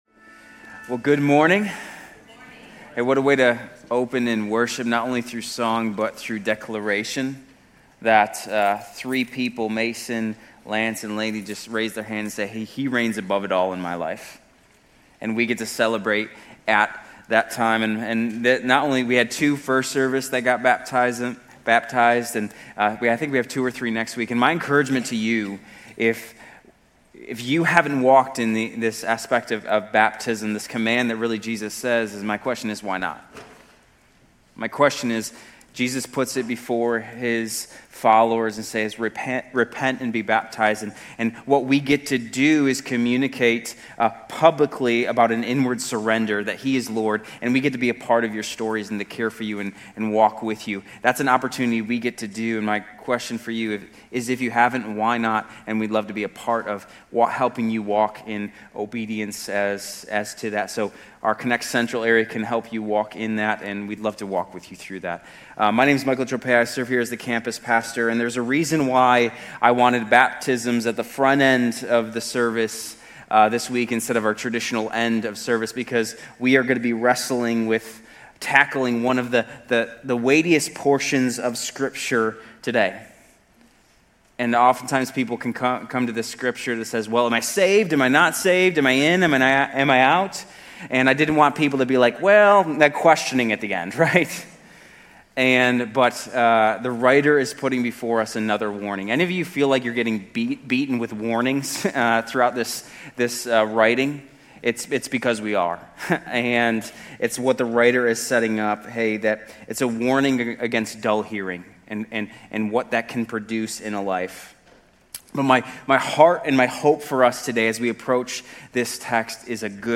Grace Community Church University Blvd Campus Sermons 10_26 University Blvd Campus Oct 27 2025 | 00:33:33 1x 00:00 / 00:33:33 Subscribe Share RSS Feed Share Link Embed